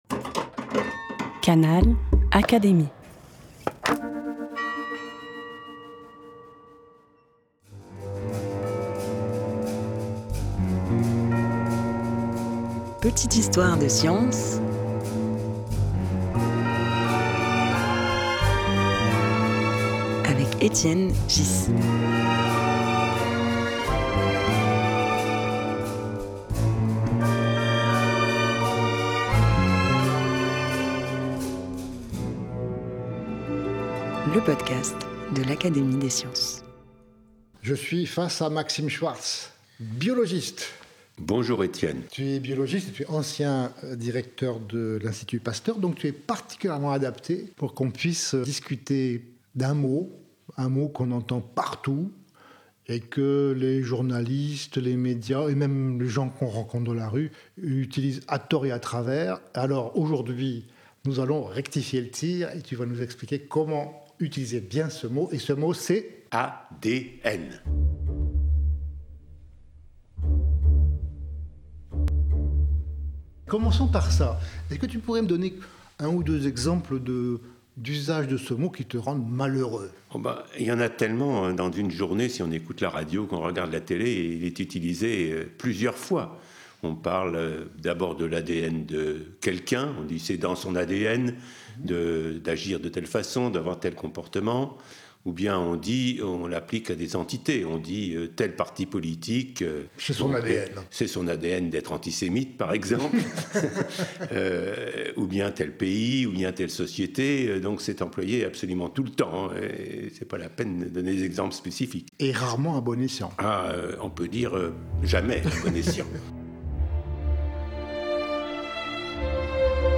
Qu’est-ce que l’ADN, de son vrai nom acide désoxyribonucléique ? Dans ce deuxième épisode de la saison consacrée aux « mots scientifiques », Étienne Ghys, Secrétaire perpétuel de l’Académie des sciences reçoit Maxime Schwartz, biologiste et ancien directeur de l’Institut Pasteur, pour discuter de ce mot et de ses fausses interprétations.
Un podcast animé par Étienne Ghys, proposé par l'Académie des sciences.